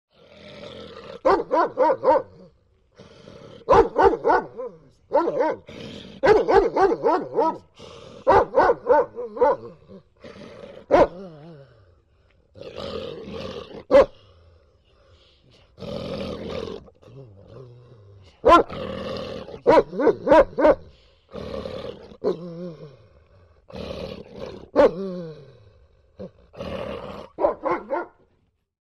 Doberman.mp3